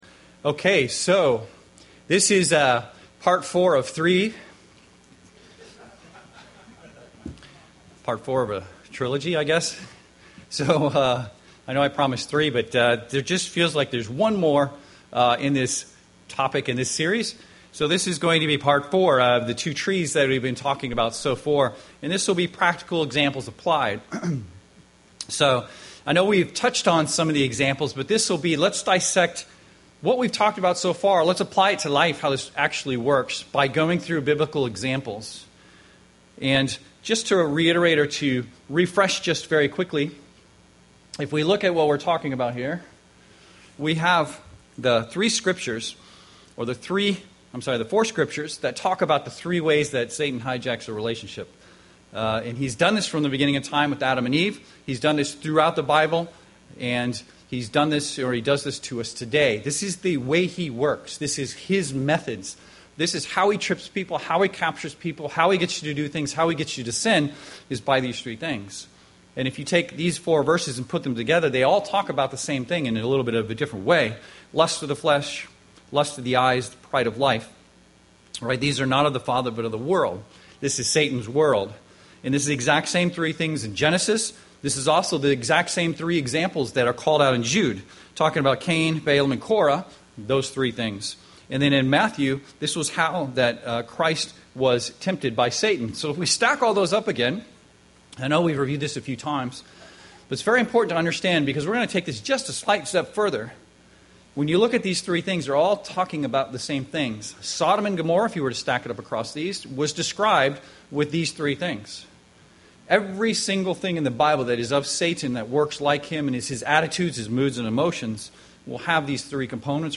Given in Seattle, WA
UCG Sermon lust pride sin Studying the bible?